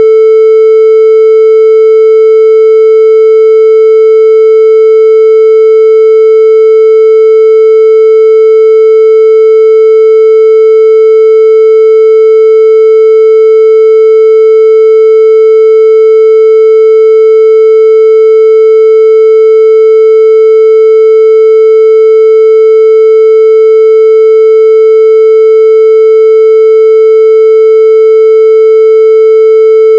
In my experience even a fairly small amount of clipping is plainly audible on a low-frequency sine.
This contains a 440 Hz sine with a peak amplitude of 1.17845 times fullscale (+1.4 dBFS), Replaygain -16.23 dB.
440hz-clip.mp3